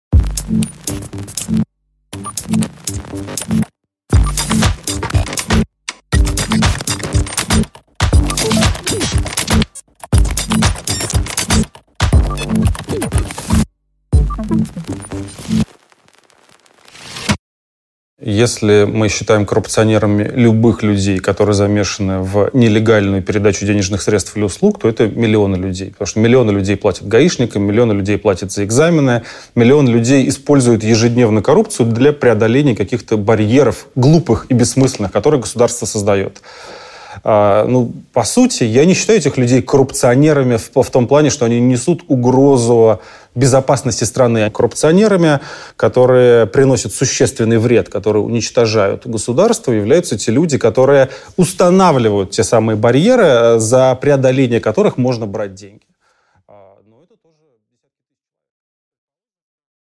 Аудиокнига О самосознании российского коррупционера | Библиотека аудиокниг